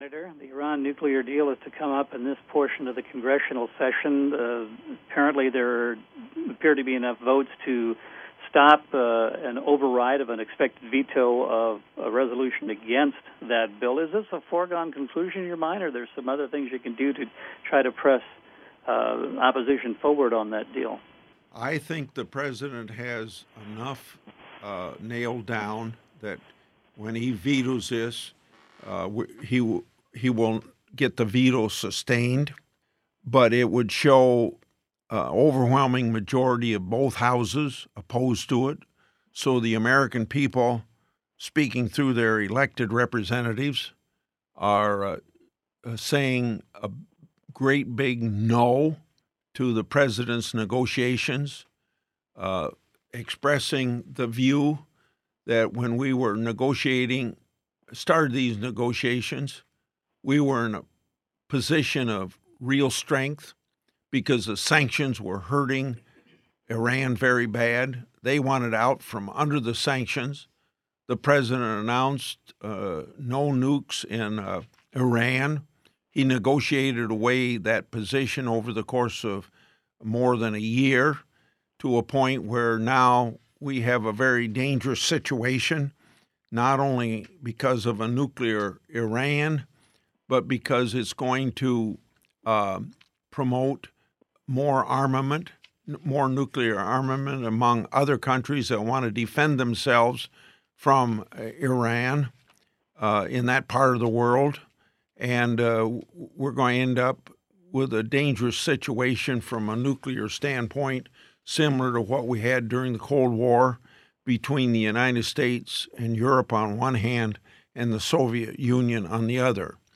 Iran, question from radio call, 9-8-15.mp3